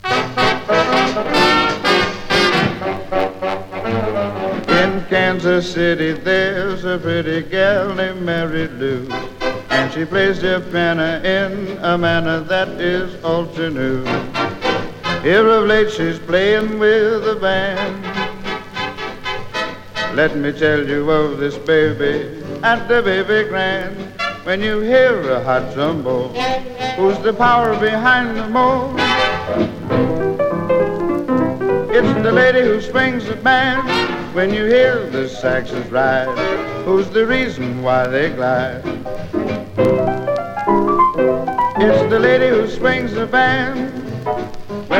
Jazz, Swing, Big Band　USA　12inchレコード　33rpm　Mono